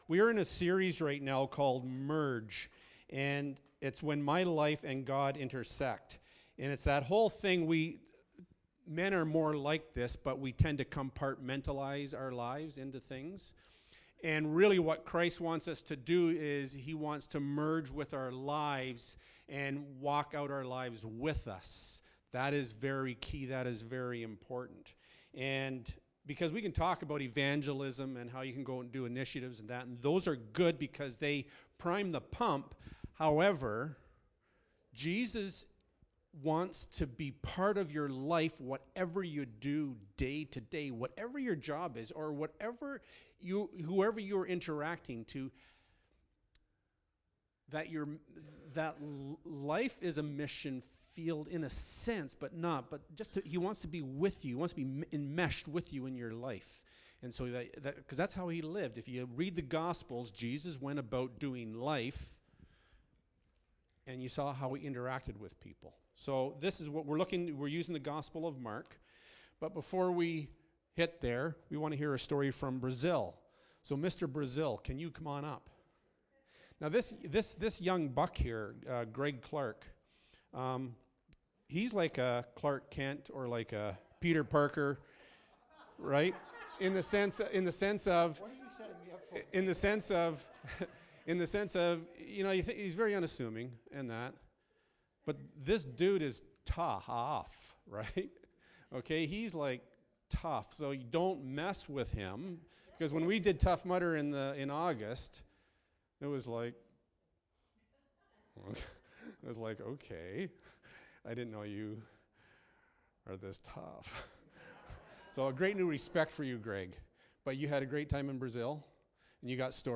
Mark 6-9 Service Type: Sunday Morning Bible Text